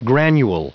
Prononciation du mot granule en anglais (fichier audio)
granule.wav